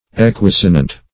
Equisonant \E*quis"o*nant\a.